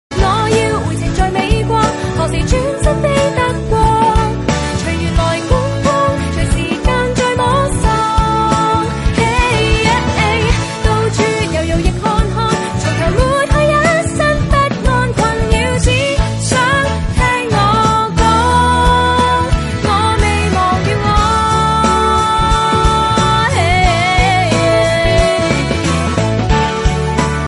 M4R铃声, MP3铃声, 华语歌曲 70 首发日期：2018-05-14 13:10 星期一